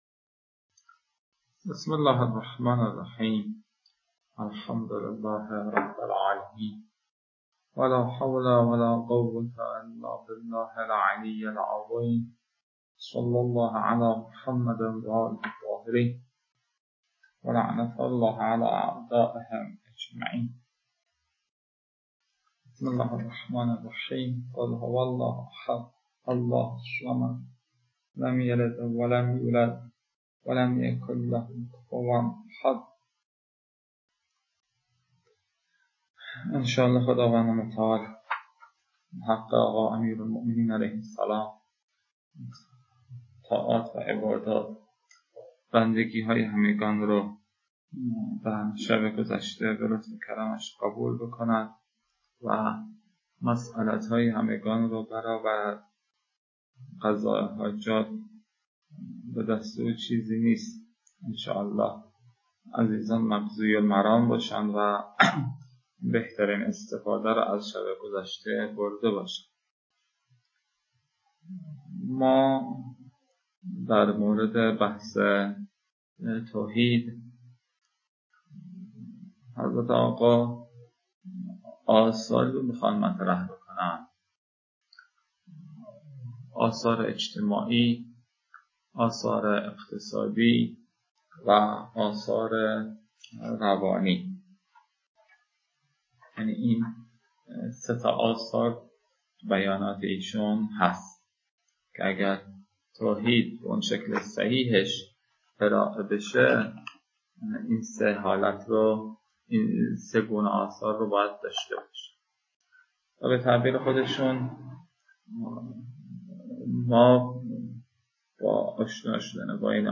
🔸 لازم به‌ذکر است که نتیجه این رویکرد، صرف پاره‌ای از بازه کلاس به رفت‌وبرگشت مبحث بین استاد و مخاطبان است که در کنار مجازی برگزارشدن کلاس، حوصله خاصی را در گوش دادن می‌طلبد. (البته فایل‌های صوتی بارها ویرایش شده‌اند تا کیفیت بهتر و مفیدتری داشته باشند.)